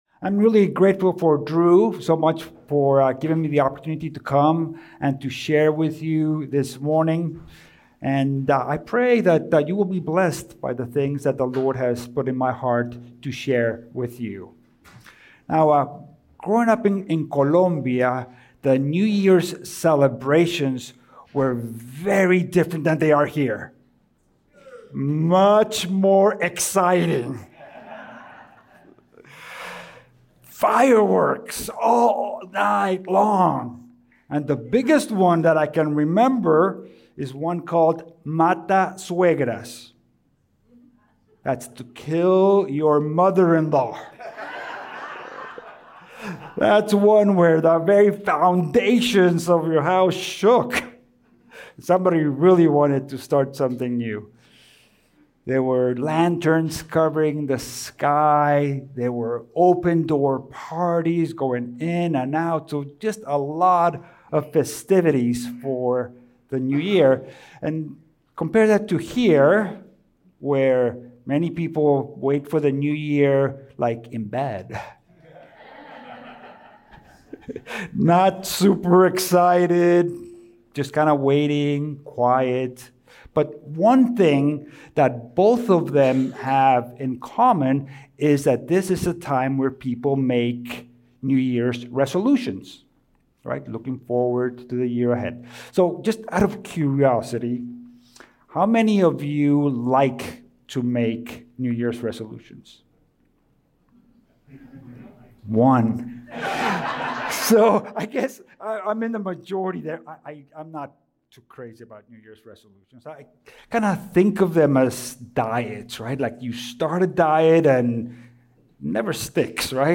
Sermons | Good News Church Georgia